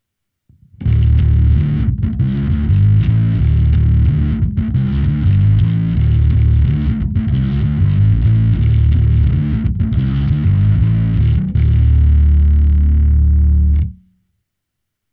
samotná simulace aparátu se zkreslením.